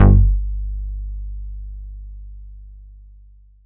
808s
Bass Pluck - R.I.P. SCREW [ G ].wav